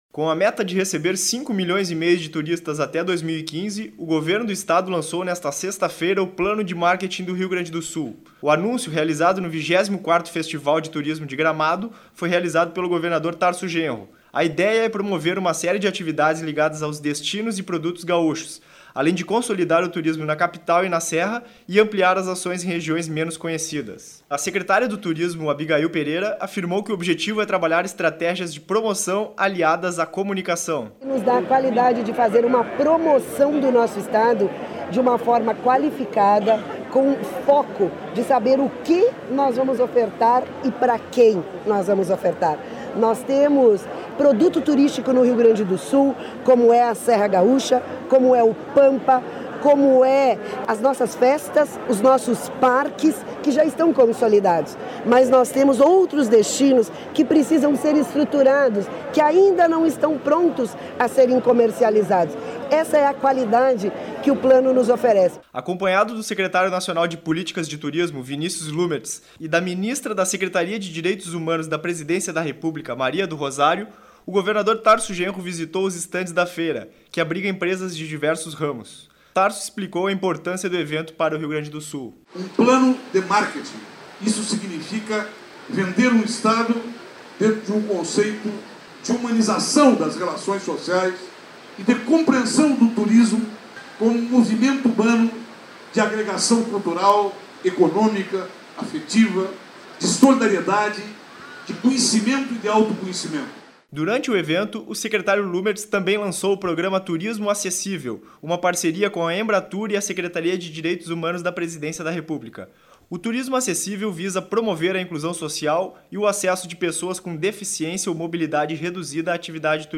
Com a meta de receber 5,5 milhões de turistas até 2015, o governo do Estado lançou nesta sexta-feira (23), o Plano de Marketing do Rio Grande do Sul. O anuncio foi realizado, no 14º Festival de Turismo de Gramado, pelo governador Tarso Genro.